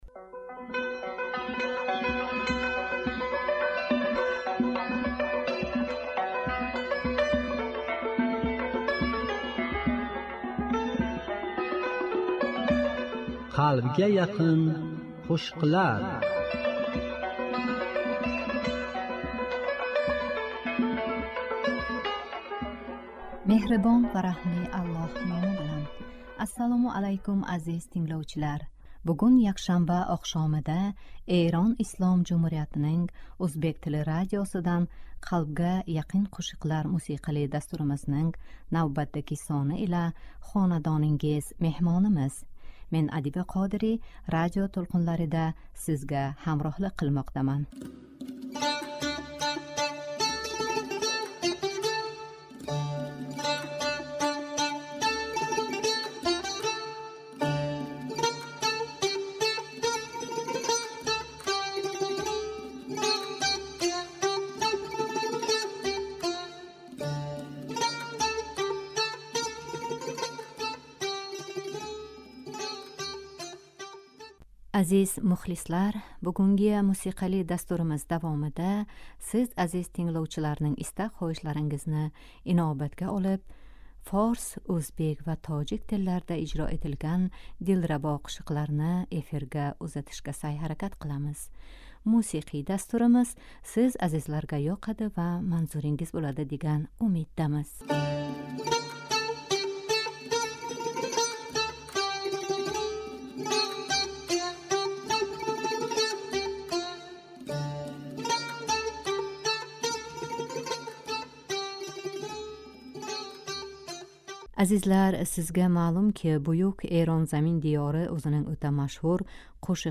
Бугун якшанба оқшомида Эрон Ислом Жумҳуриятининг ўзбек тили радиосидан "Қалбга яқин қўшиқлар"мусиқали дастуримизнинг навбатдаги сони ила хонадонингиз меҳмонимиз Меҳрибон ва раҳмли Аллоҳ номи билан!
Азизлар, бугунги мусиқали дастуримиз давомида сиз азиз тингловчиларнинг истак -хоҳишларингизни инобатга олиб форс, ўзбек ва тожик тилларда ижро этилган дилрабо қўшиқларни эфирга ўзатишга сайъ-ҳаракат қиламиз.